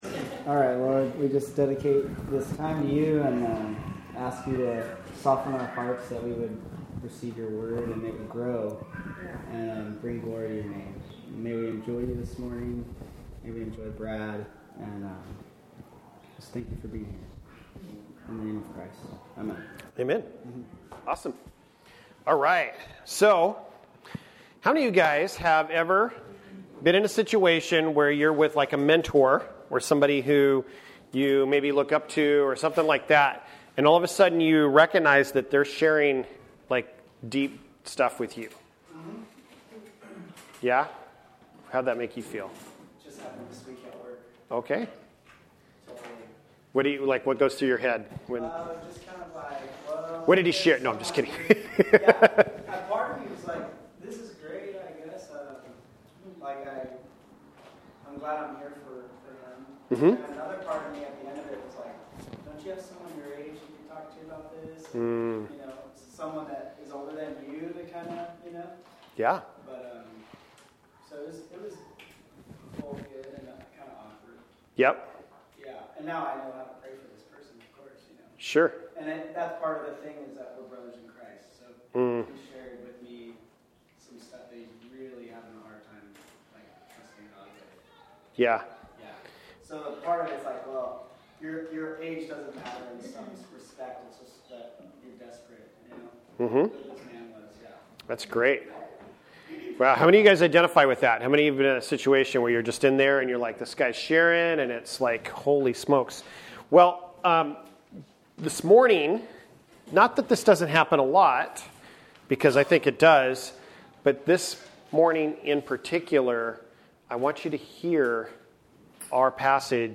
Passage: genesis 4:4-9, genesis 6:22, genesis 7:1-24, genesis 8:1-22, Genesis 9:1-16 Service Type: Sunday Morning